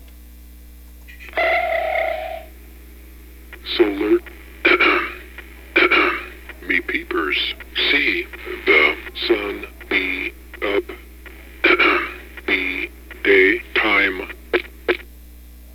It talks not itself, but talks through the Enunciator, as all modules do.
BBALLBLU.GIF, 139B Ships Sonar Ping is the "Attention" alert.
BBALLBLU.GIF, 139B There is ample "clearing of the throats" to separate thoughts.
BBALLBLU.GIF, 139B The end of every sentence is punctuated with a vocal period. Actually, two here.